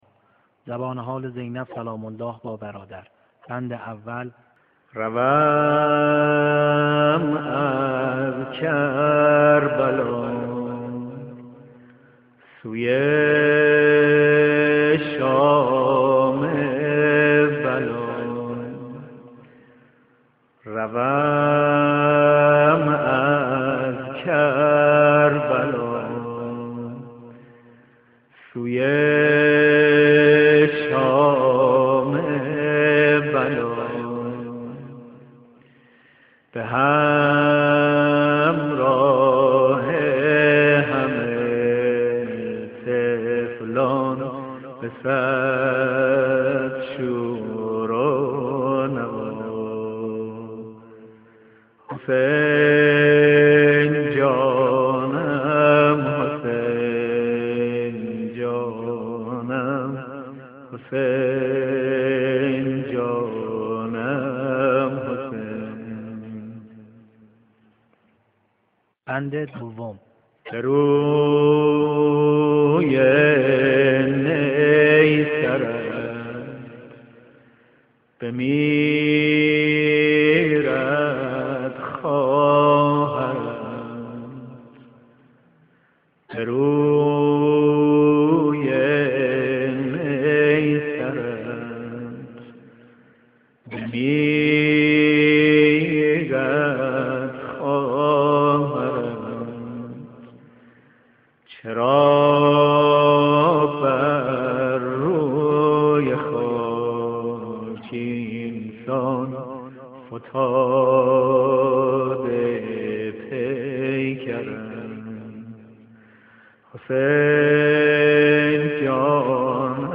ذکر سقائی ( سبک شماره 8) حضرت زینب